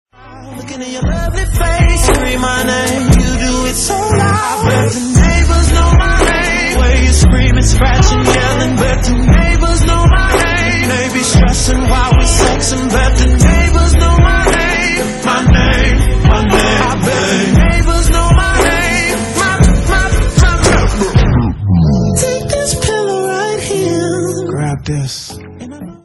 Reduced quality: yes
It is of a lower quality than the original recording.